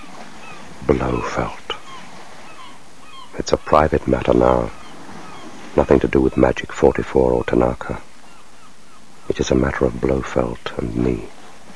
In December of 1990, BBC Radio 4 produced a radio play of You Only Live Twice starring Michael Jayston as James Bond.
Michael Jayston as Bond